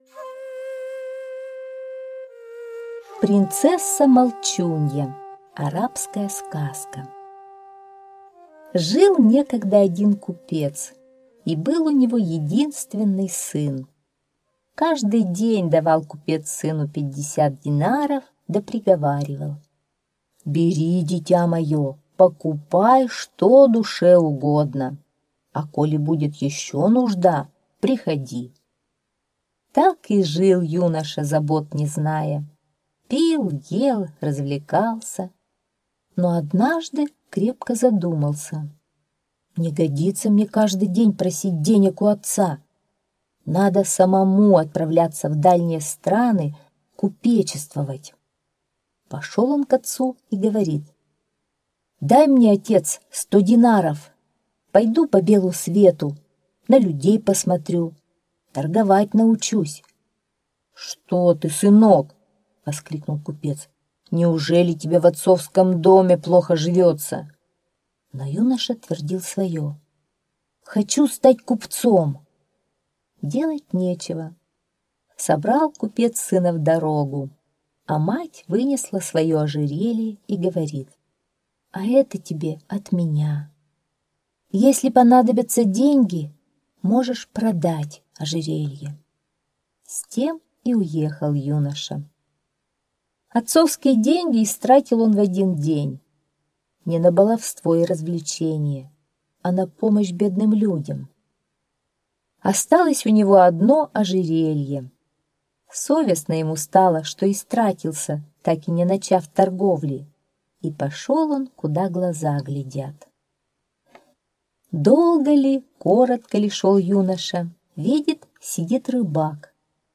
Принцесса-молчунья - арабская аудиосказка - слушать онлайн